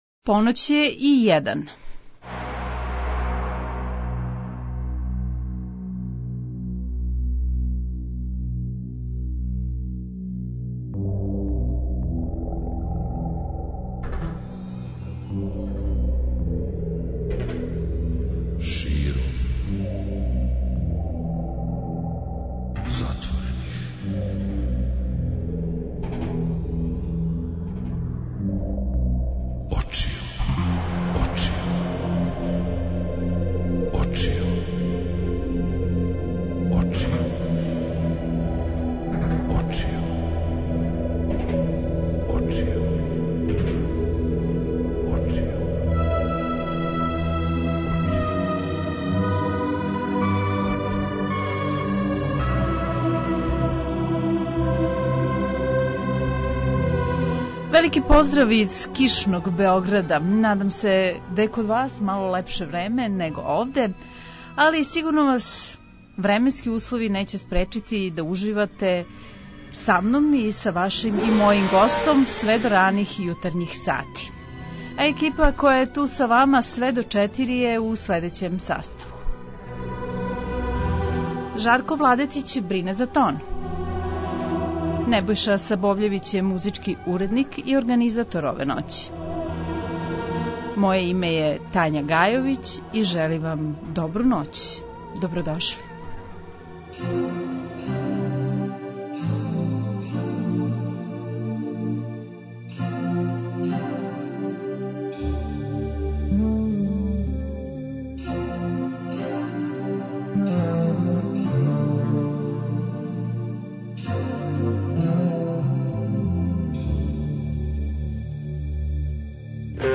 Гост: Владе Ђуровић - Ђура, бивши југословенски кошаркаш и кошаркашки тренер.
преузми : 56.87 MB Широм затворених очију Autor: Београд 202 Ноћни програм Београда 202 [ детаљније ] Све епизоде серијала Београд 202 Говор и музика Састанак наше радијске заједнице We care about disco!!!